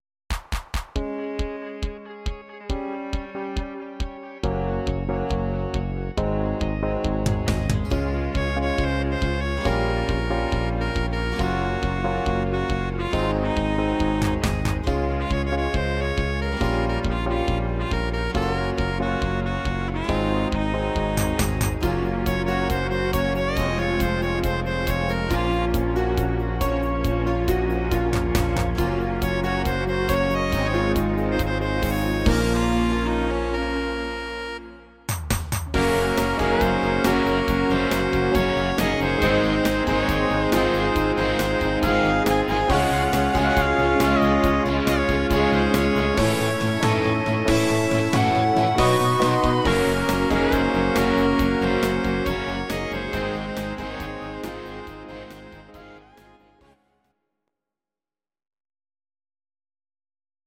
Please note: no vocals and no karaoke included.
Your-Mix: Rock (2970)